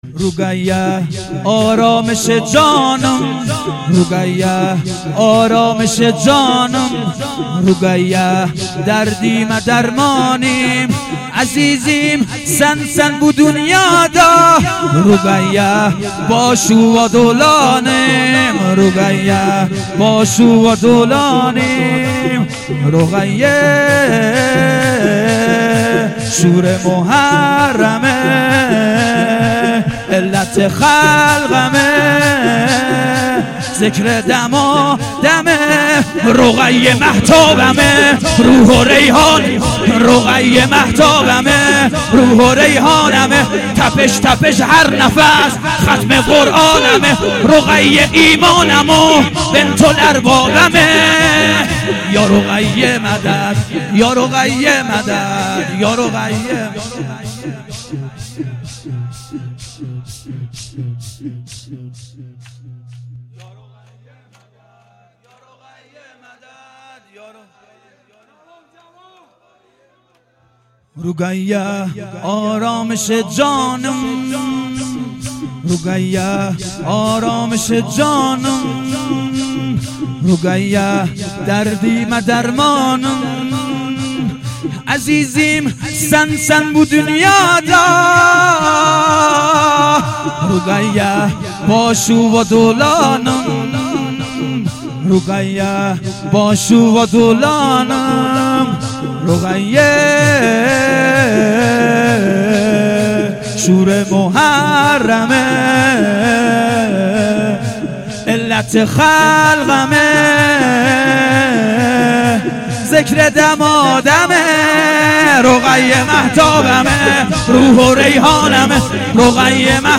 اصوات مراسم سیاهپوشان ودهه اول محرم۹۷هییت شباب الحسین